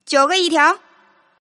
Index of /client/common_mahjong_tianjin/mahjongwuqing/update/1162/res/sfx/tianjin/woman/